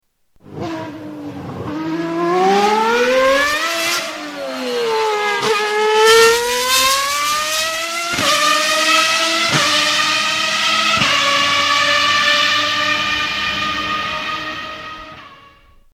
Ferrari Flyby